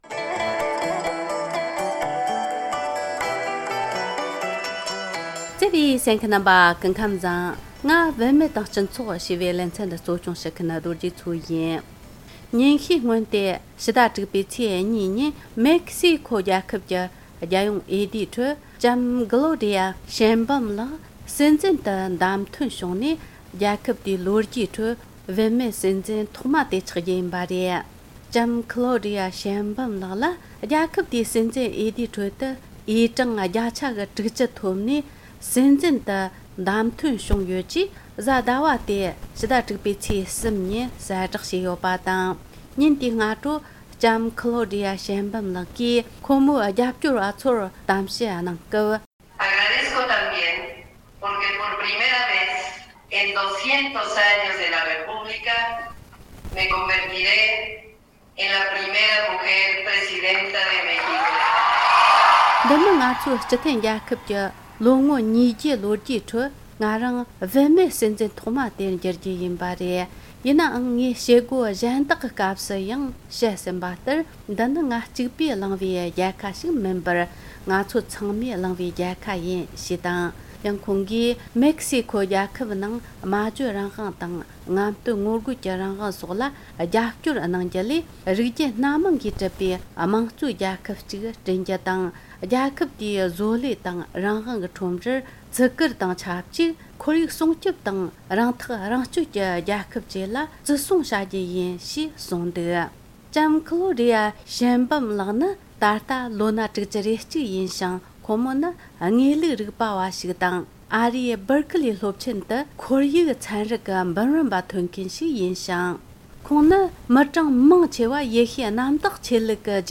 འབྲེལ་ཡོད་མི་སྣར་བཅར་འདྲི་དང་བཅས་ཕྱོགས་སྒྲིག་བྱས་པར་གསན་རོགས་གནོངས།